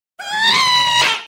Ghast Scream Minecraft Meme sound effects free download
Ghast Scream Minecraft - Meme Sound Effect